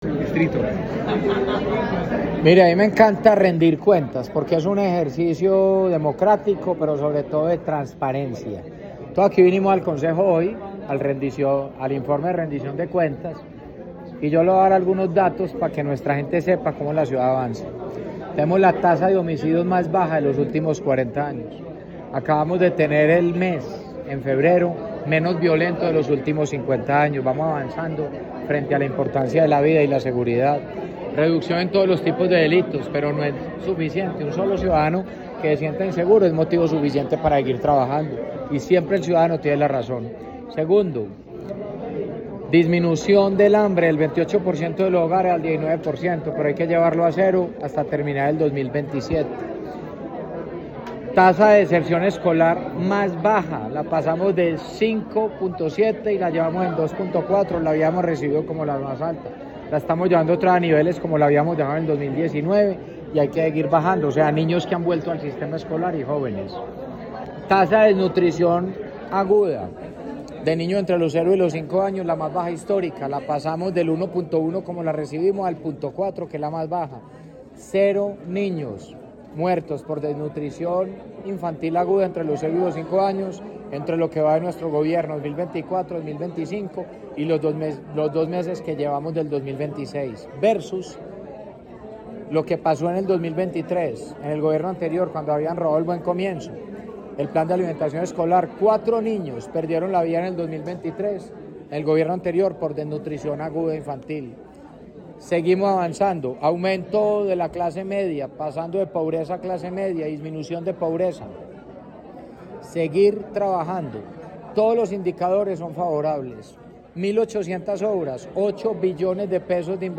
El alcalde Federico Gutiérrez Zuluaga presentó los resultados de su gestión en 2025, dentro del ejercicio anual de rendición de cuentas ante el Concejo de Medellín, en el que destacó avances concretos en infraestructura, impacto social y desarrollo económico, que se traducen en la transformación visible del territorio.
Declaraciones-alcalde-de-Medellin-Federico-Gutierrez-1.mp3